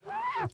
wash1.ogg